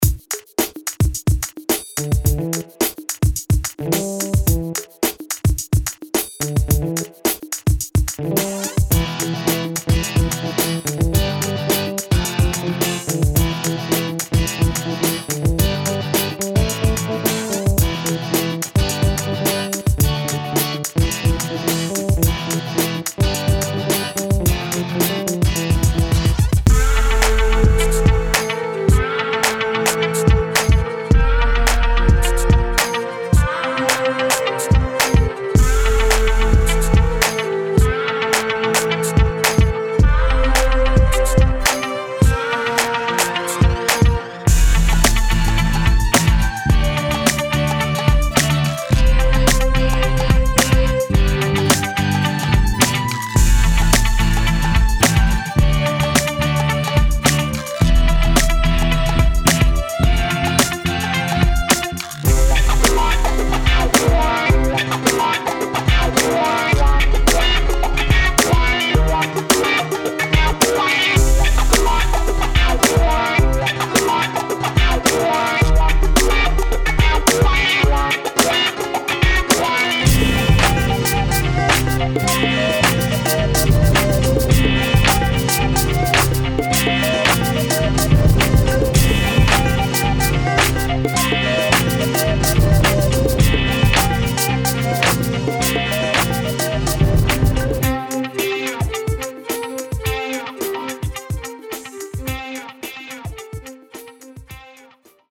R&B + Funk Категория: Написание музыки